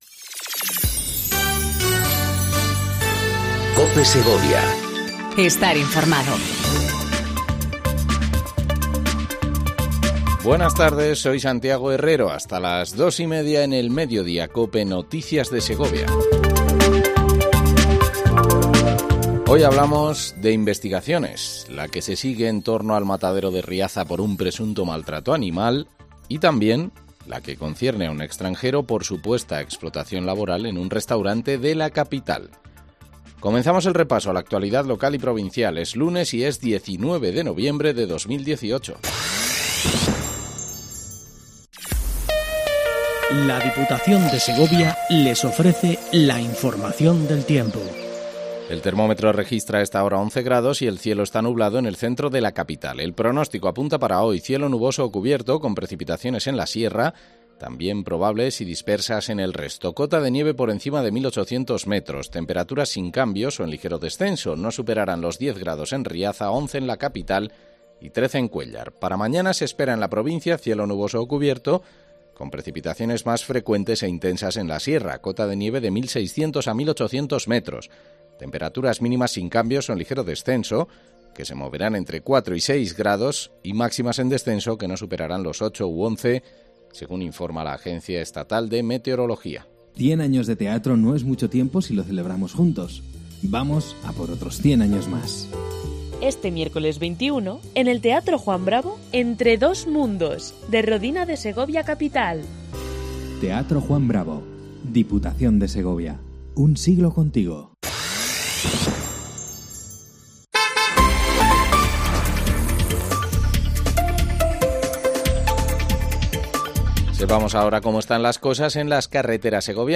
INFORMATIVO MEDIODÍA EN COPE SEGOVIA 14:20 DEL 19/11/18